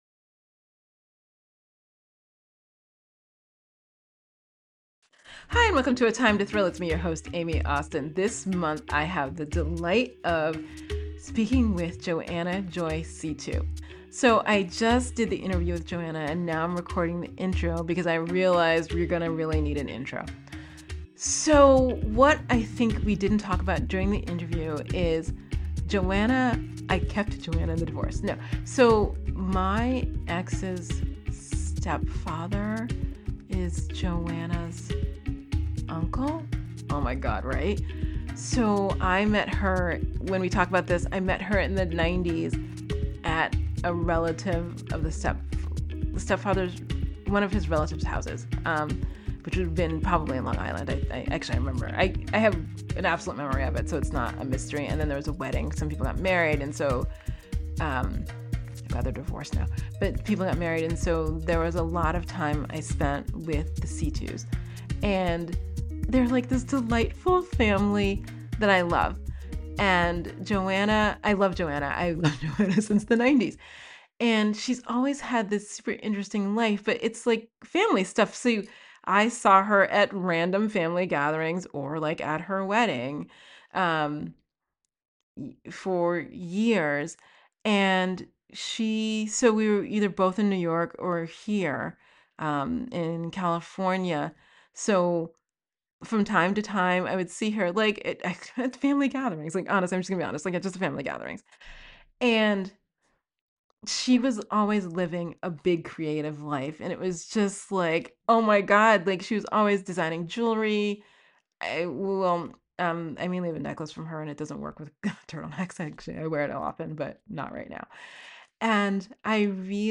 A Time to Thrill – Conversation